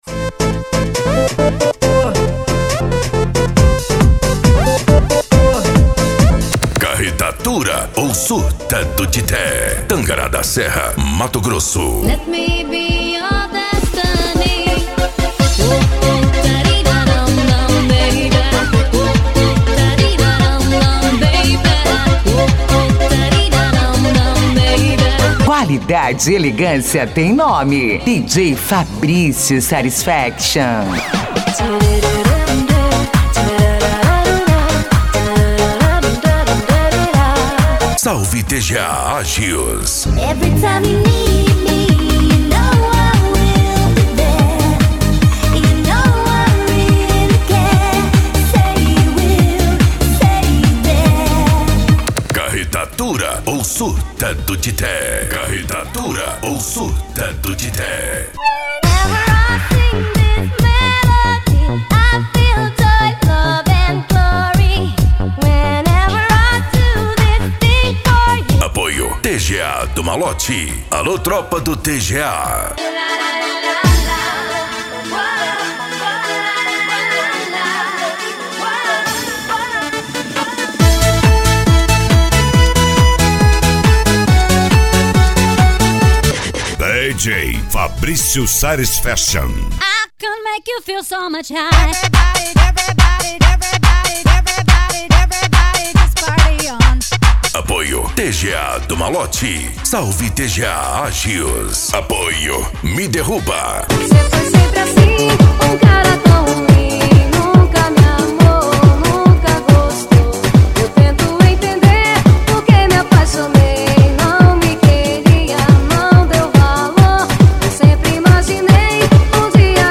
Euro Dance
Funk
Sets Mixados